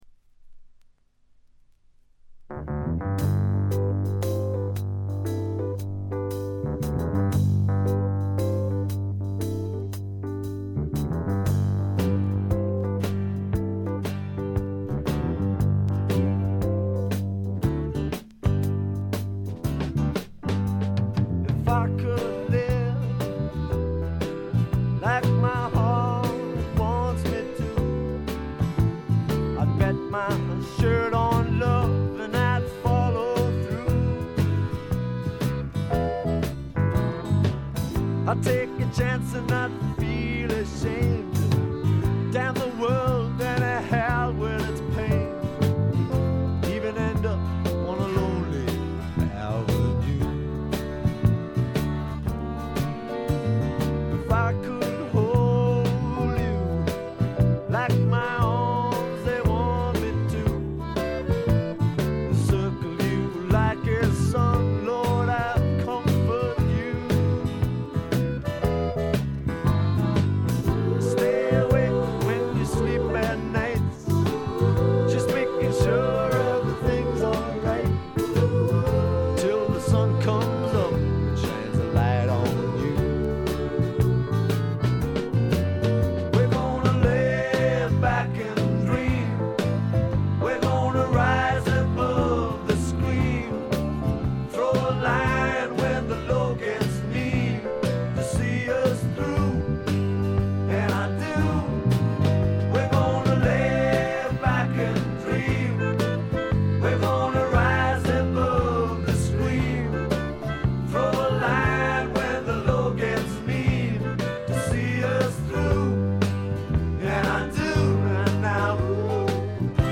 軽いチリプチ少々。
パブロック風味満載、いぶし銀の英国フォークロックです。
試聴曲は現品からの取り込み音源です。